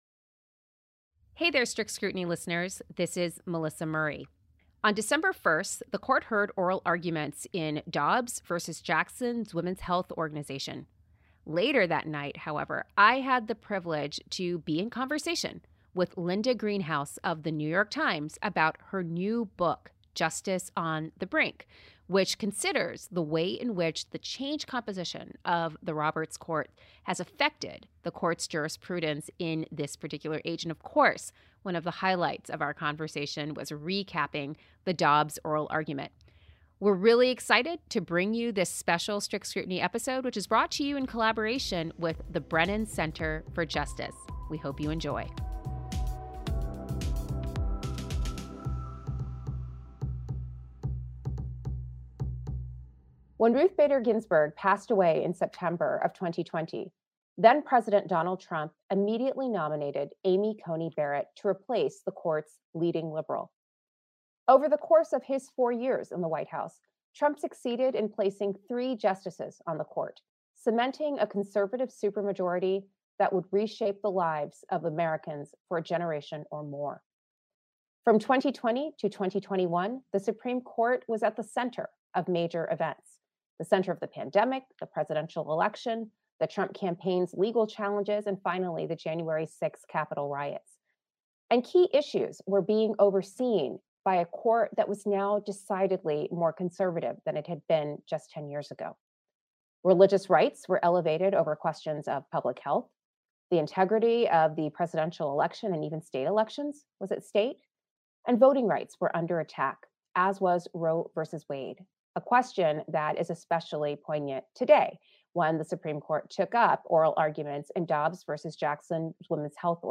This conversation was originally a live, virtual event from the Brennen Center for Justice, produced in partnership with New York University’s John Brademas Center.